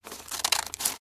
Here you’ll Find a wide Collection of Scissors Sound Effects ranging from Scissors Cutting and Snipping through to Cutting Cardboard or Bond Paper.
Scissors-cutting-paper-2.mp3